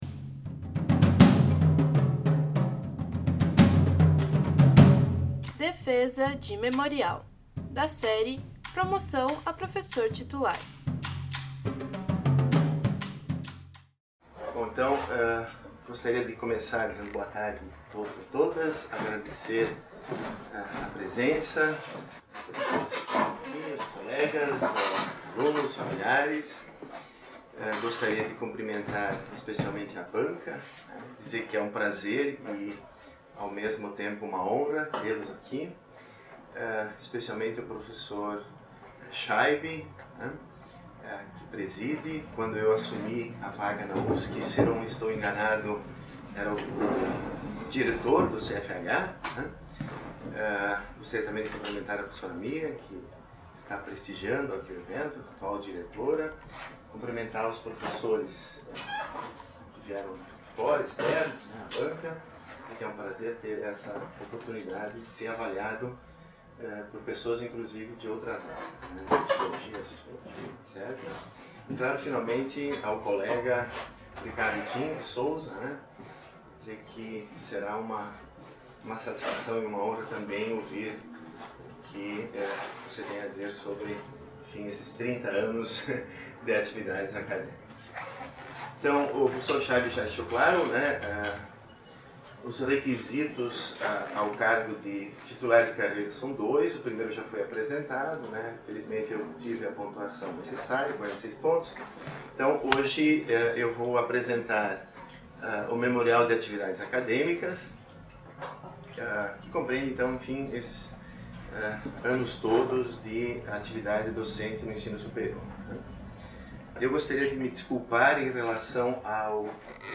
no Auditório do MArquE. Área de atuação: Ética, Filosofia Política e Filosofia da Linguagem.